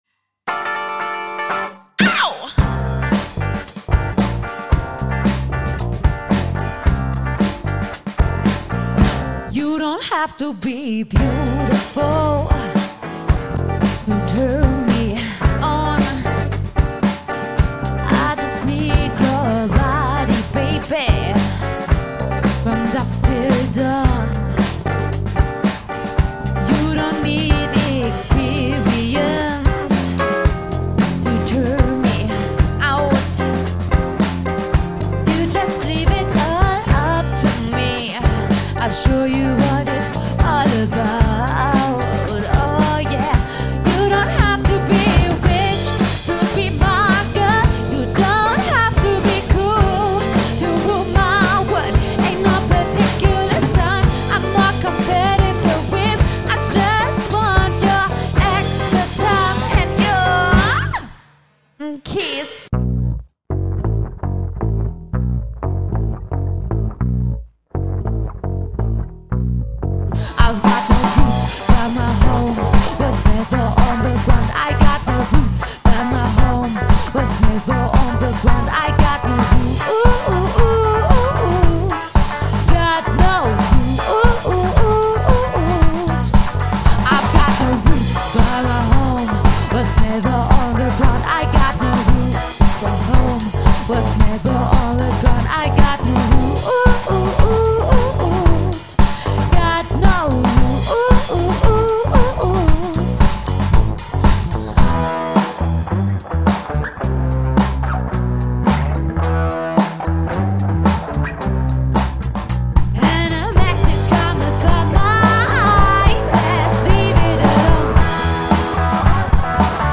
Livemusik aus Mayen/Germany - Hits f�r Feste/Partys/Dance